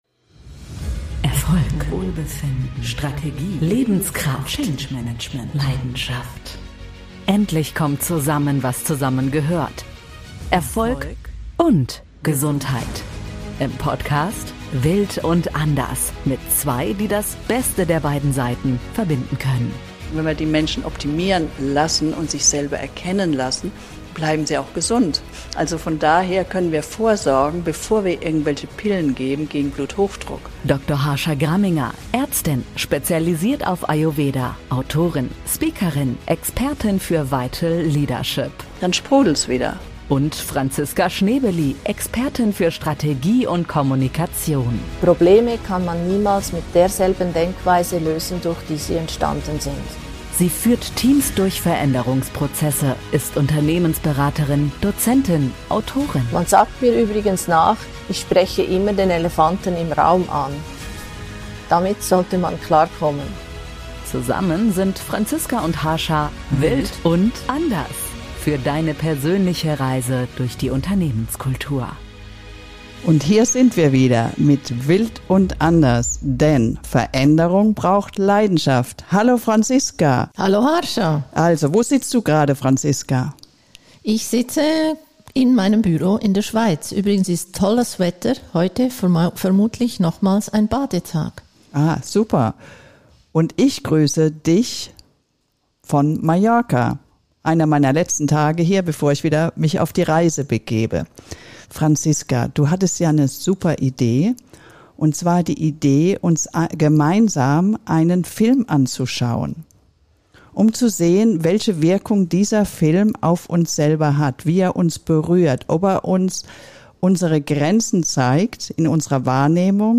In dieser Episode von WILD & ANDERS sprechen die beiden Freundinnen über Freundschaft!